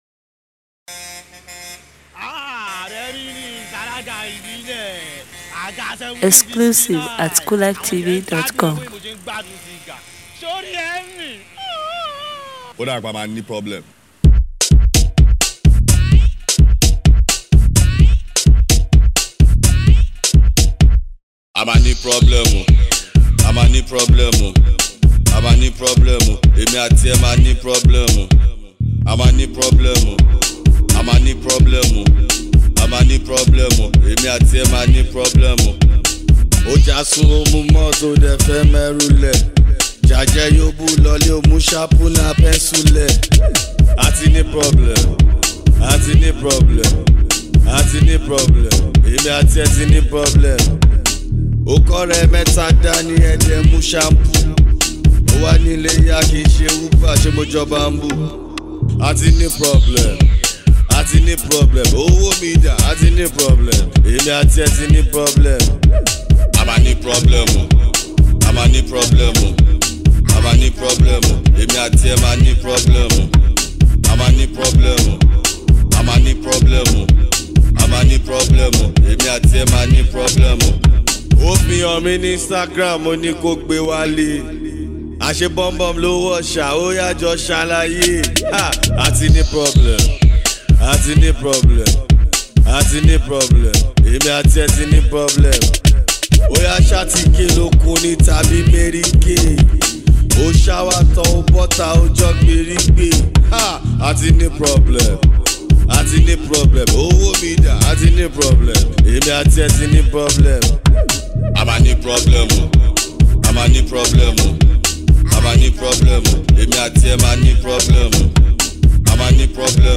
Shaku Shaku